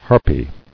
[Har·py]